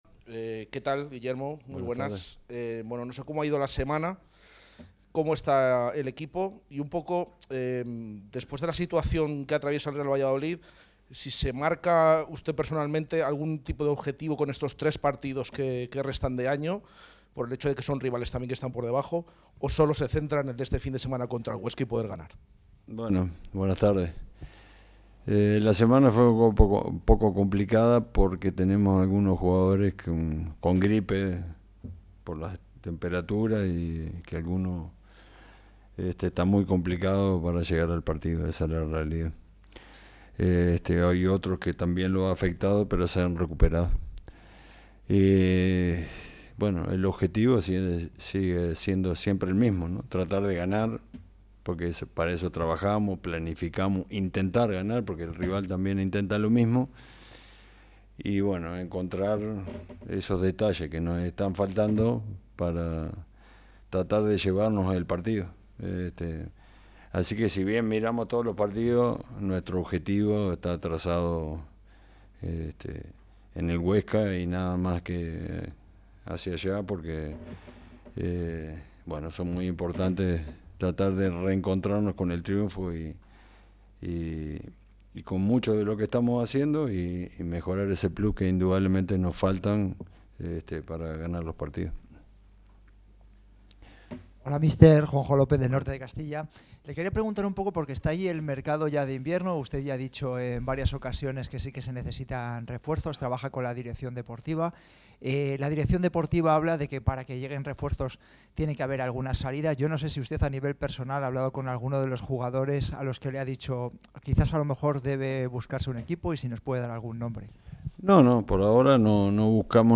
en la sala de prensa de Zorrilla a dos días del duelo ante la Sociedad Deportiva Huesca